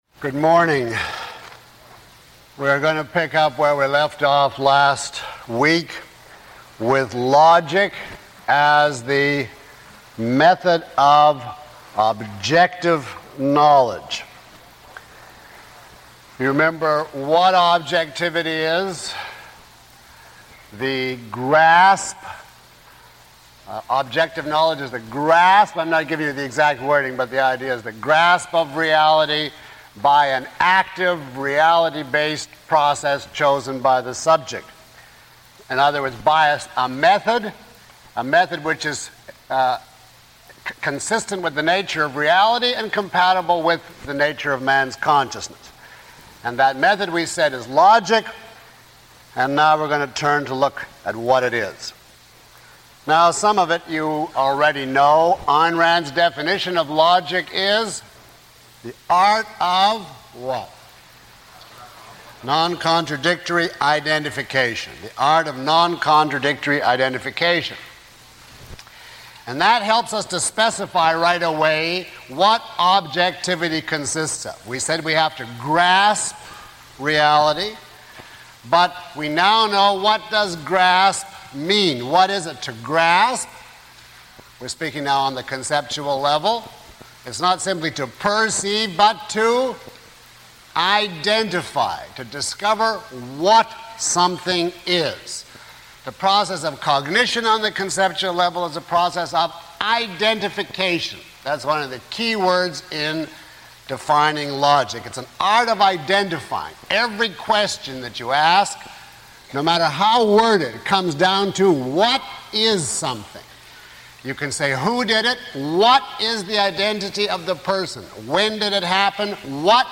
[Lecture Four] Objectivism: The State of the Art